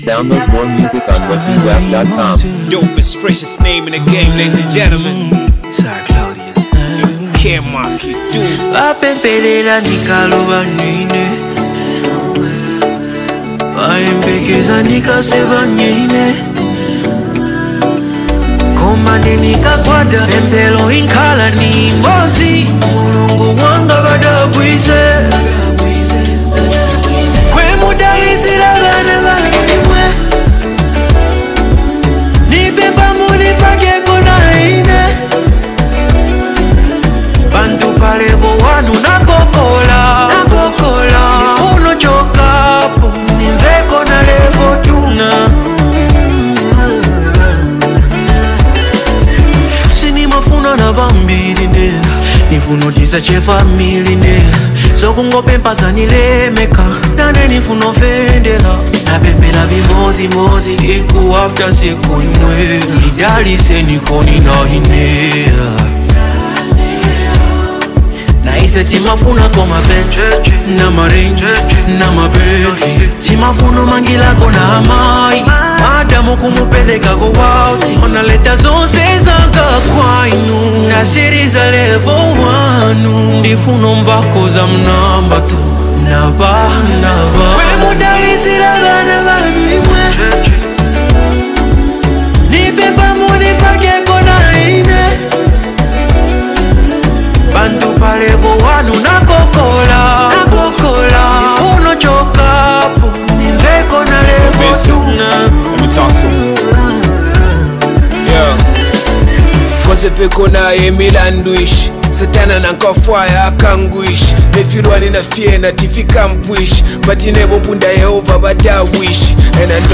soul-stirring anthem of hope and faith